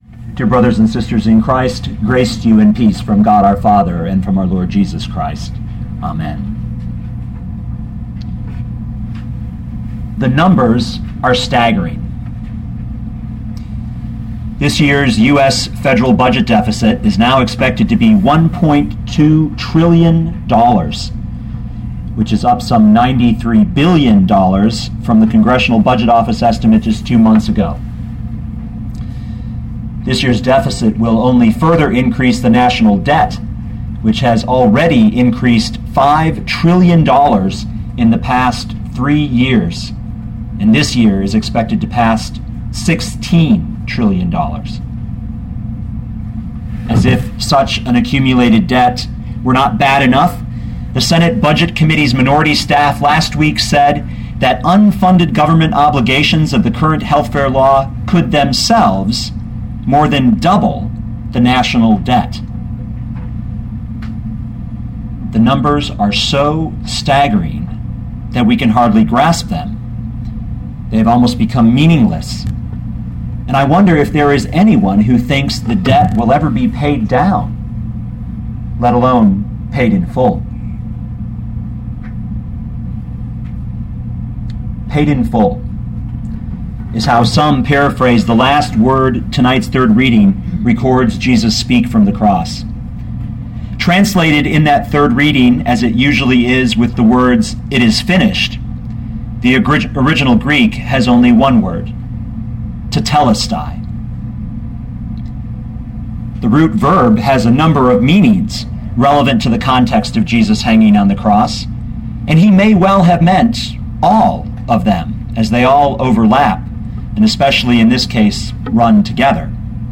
2012 John 19:30 Listen to the sermon with the player below, or, download the audio.